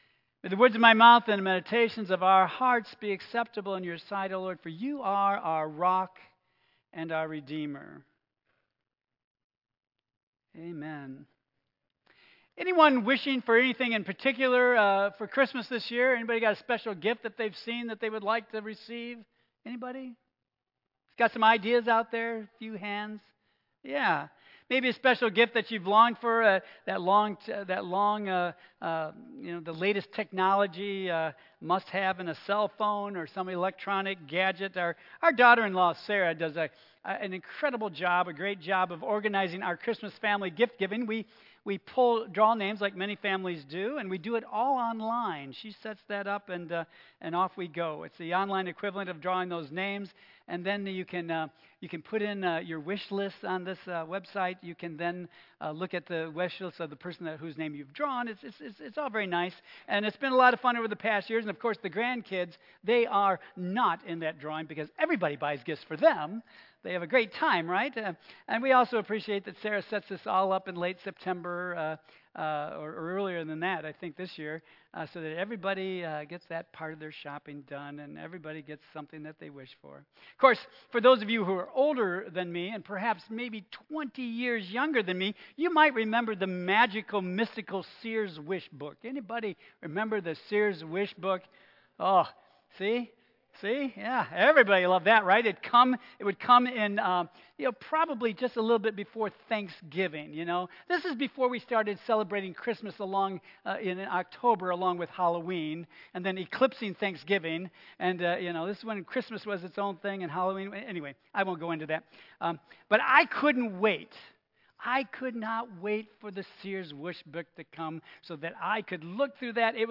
Tagged with Michigan , Sermon , Waterford Central United Methodist Church , Worship Audio (MP3) 7 MB Previous Give It Up for Jesus Next What Are We Waiting For?